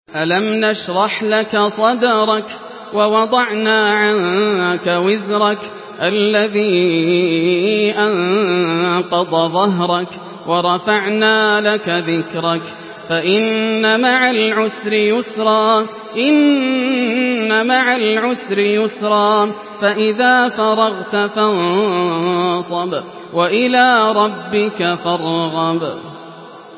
Surah Inşirah MP3 by Yasser Al Dosari in Hafs An Asim narration.
Murattal